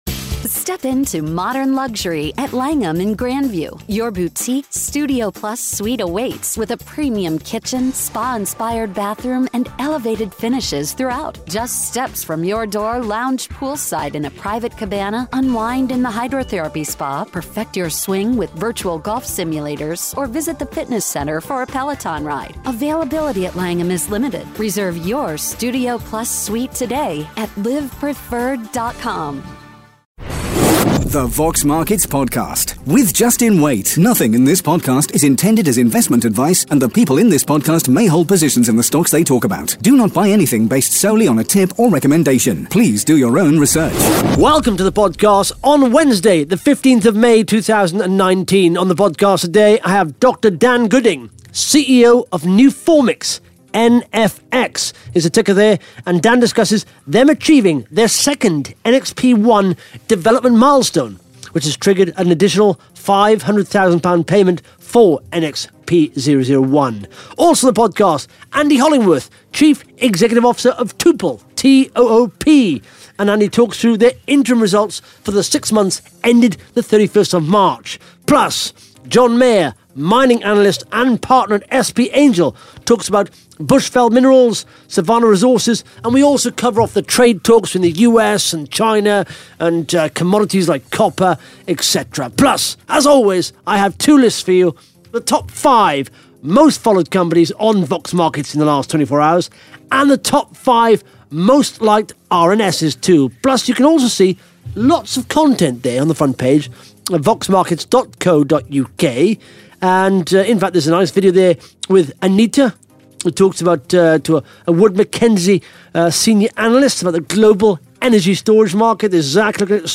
(Interview starts at 13 minutes 52 seconds)